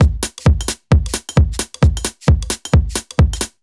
Index of /musicradar/uk-garage-samples/132bpm Lines n Loops/Beats
GA_BeatD132-06.wav